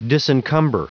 Prononciation du mot disencumber en anglais (fichier audio)
Prononciation du mot : disencumber